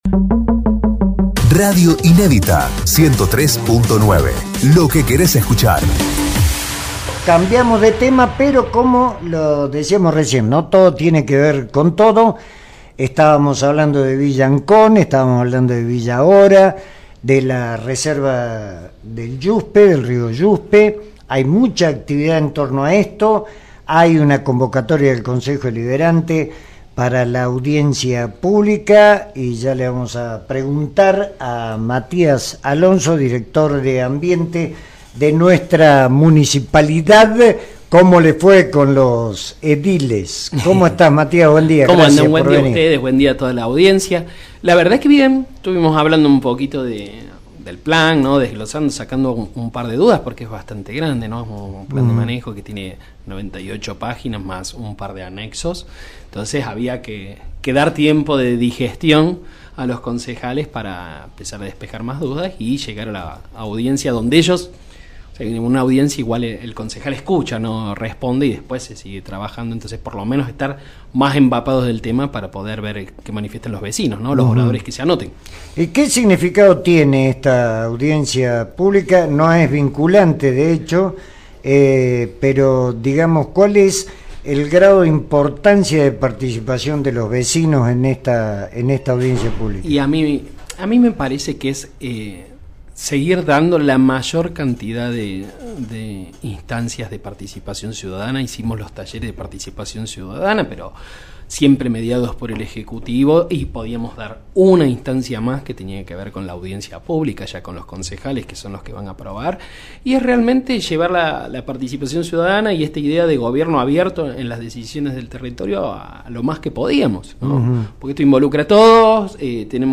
En Agenda (LaV 9hs) dialogamos con Matías Alonso , Sub Secretario de Ambiente y Economía Circular de la Municipalidad de Cosquin sobre esta convocatoria